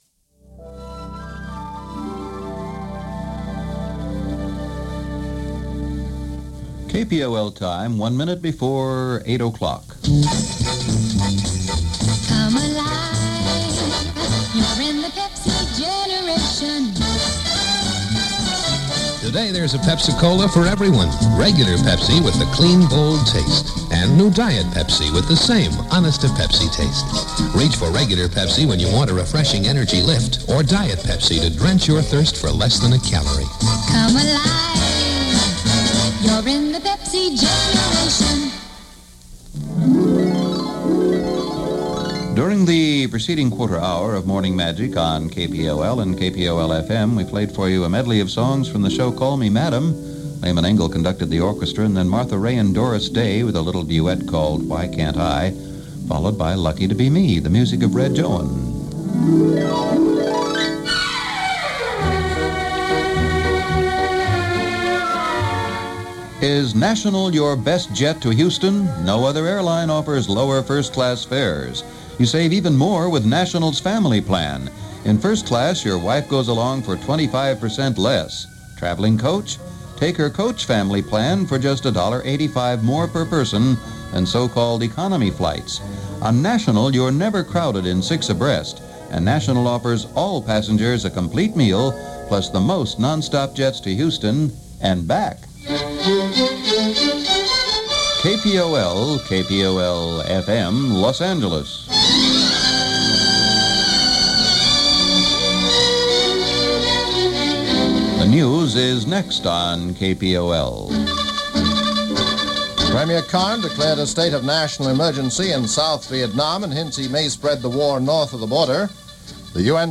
And there’s this cheery music in the background.